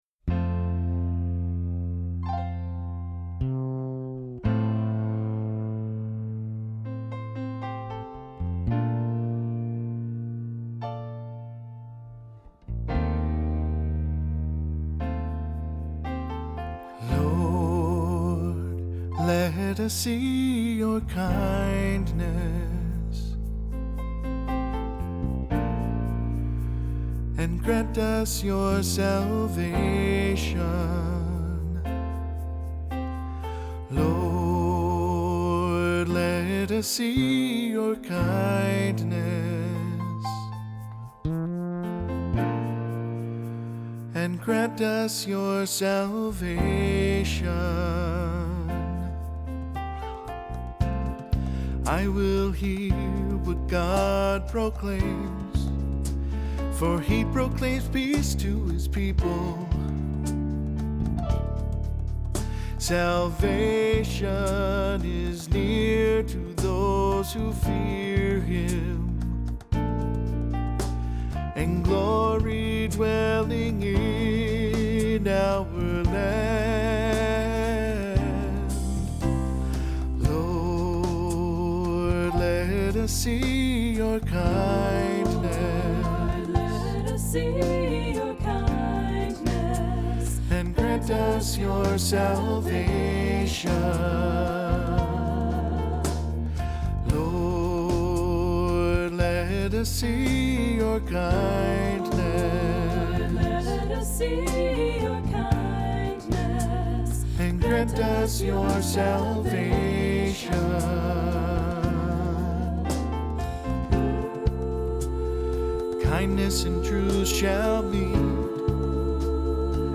Accompaniment:      Piano
Music Category:      Christian
Ê For cantor or soloist